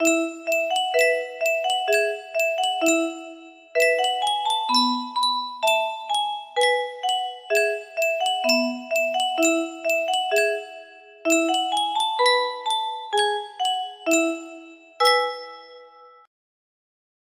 Ca Ira! 1326 music box melody